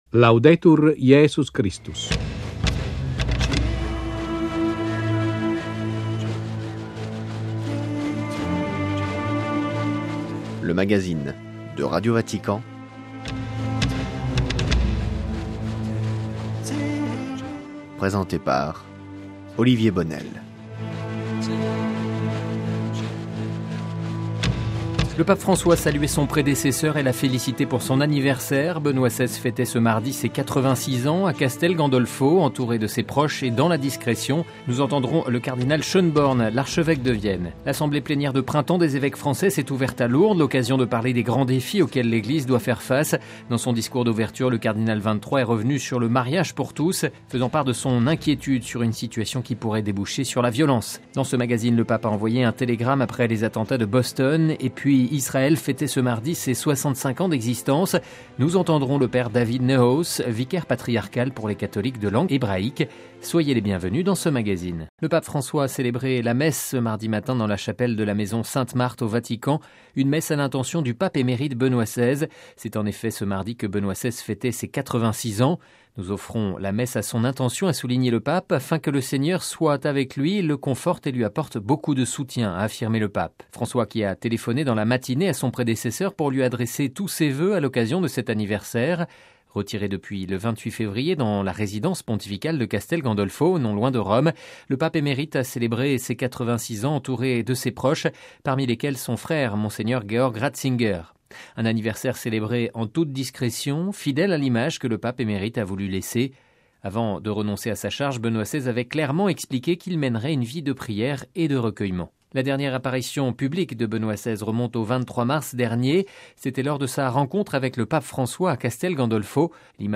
Mariage pour tous en France, le débat se radicalise, entretien